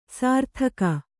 ♪ sārthaka